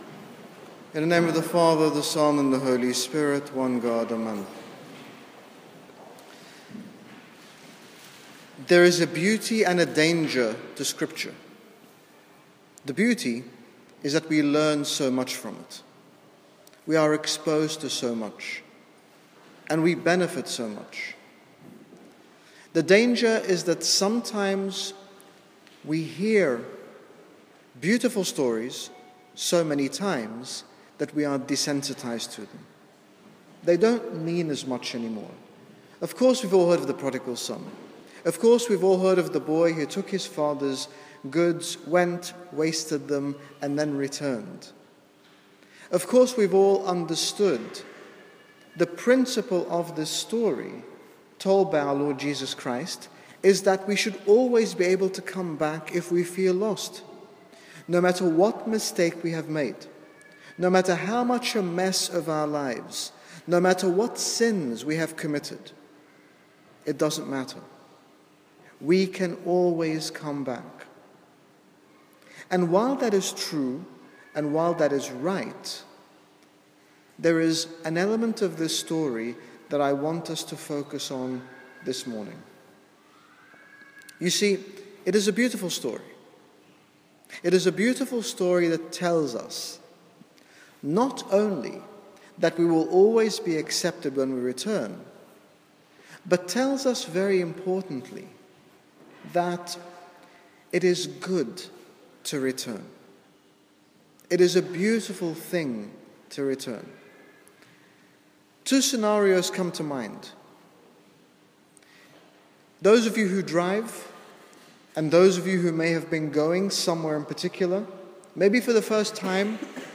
In this short sermon about the Prodigal Son, His Grace Bishop Angaelos, General Bishop of the Coptic Orthodox Church in the United Kingdom talks about the immense love God has for each one of us, regardless of our shortcomings, highlighting that if we return to our Father in repentance He not only welcomes us back but lovingly and willingly restores us to who we are.